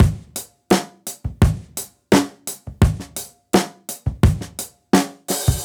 Index of /musicradar/dusty-funk-samples/Beats/85bpm
DF_BeatD_85-01.wav